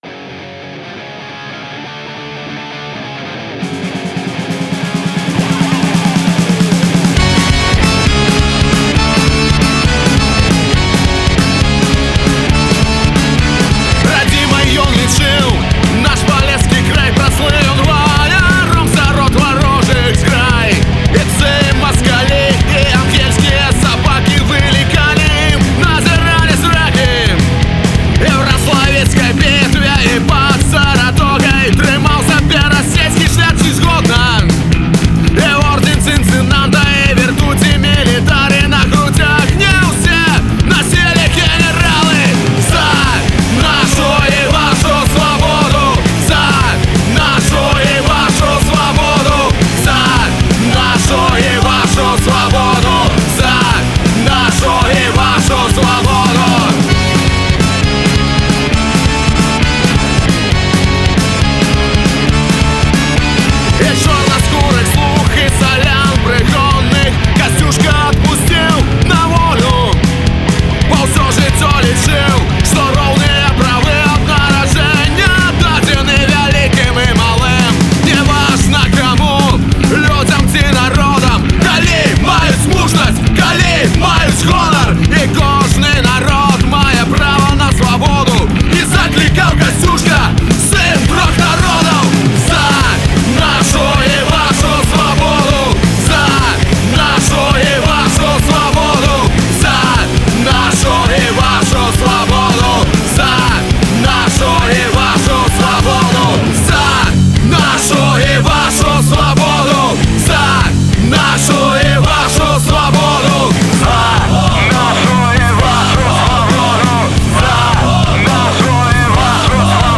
Фольк-панк гурт заснаваны ў 2012 ў Горадні.